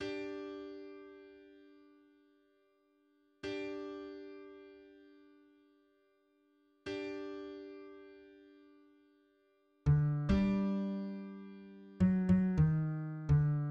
<< %{ \new Staff \with {instrumentName = "S A" shortInstrumentName = "S A"} << \set Staff.midiMinimumVolume = #0.3 \set Staff.midiMaximumVolume = #0.7 \set Score.currentBarNumber = #1 \bar "" \tempo 4=70 \time 4/4 \key bes \major \new Voice = "s1" \relative c '' { \voiceOne bes2 a bes a bes4 a c a bes2 a } \new Voice = "s2" \relative c '{ \voiceTwo ees2 d ees d ees4 d f d ees2 d } >> \new Lyrics \lyricsto "s1" {\set fontSize = #-2 s' brent s' brent und- zer shte- tl bre -nt } %} \new Staff \with {instrumentName = "A" shortInstrumentName = "A"} << \set Staff.midiMinimumVolume = #0.3 \set Staff.midiMaximumVolume = #0.5 \set Score.currentBarNumber = #1 \bar "" \tempo 4=70 \time 4/4 \key bes \major \new Voice = "a1" \fixed c ' { \voiceOne g1 g g g } \new Voice = "a2" \fixed c ' { \voiceTwo d1 d d d } >> \new Lyrics \lyricsto "a1" {\set fontSize = #-2 } \repeat volta 2 \new Staff \with {instrumentName = "T" shortInstrumentName = "T"} << \set Staff.midiMinimumVolume = #0.3 \set Staff.midiMaximumVolume = #0.5 \set Score.currentBarNumber = #1 \bar "" \tempo 4=70 \time 4/4 \key bes \major \new Voice = "t1" \fixed c ' { \voiceOne g1 g g g } \new Voice = "t2" \fixed c ' { \voiceTwo d1 d d d } >> \new Lyrics \lyricsto "t1" {\set fontSize = #-2 } \repeat volta 2 \new Staff \with {midiInstrument = #"acoustic bass" instrumentName = "B" shortInstrumentName = "B"} << \set Staff.midiMinimumVolume = #14.7 \set Staff.midiMaximumVolume = #15.9 \set Score.currentBarNumber = #1 \bar "" \tempo 4=70 \time 4/4 \key bes \major \clef bass \new Voice = "b1"{ \voiceOne r1 r r2 r4 r8 d g2 \tuplet 3/2 {fis8 fis ees~} ees d } >> \new Lyrics \lyricsto "b1" {\set fontSize = #-2 Es brent, bri- der lekh- es brent oy und- der or- em shtet- tl ne- bekh brent } \new Lyrics \lyricsto "b1" {\set fontSize = #-2 _ _ _ _ _ _ _ es ken kho- li- le ku- men der mo- ment und- zer shtot mit undz tsu- za- maen zol } >> \midi{}